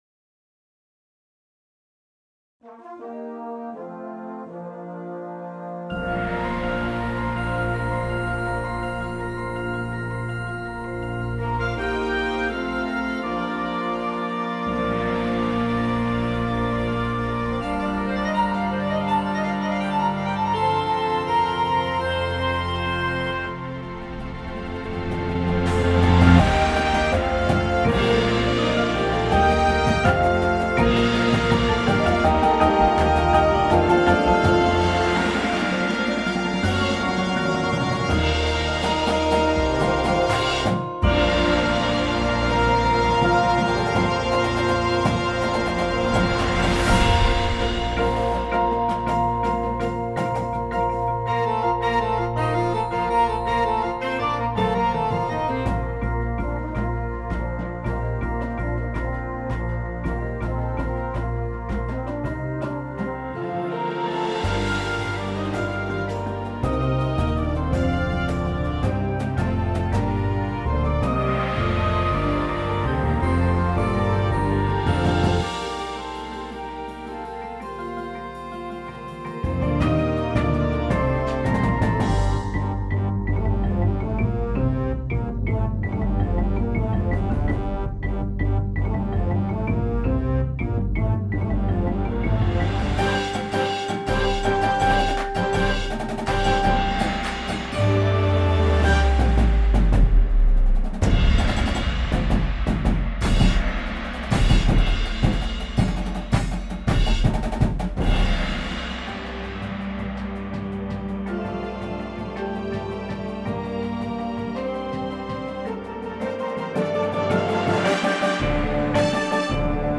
INSTRUMENTATION:
• Flute
• Trumpet 1
• Horn in F
• Tuba
• Snare Drum
• Sound Effect Samples
• Marimba – Two parts
• Vibraphone – Two parts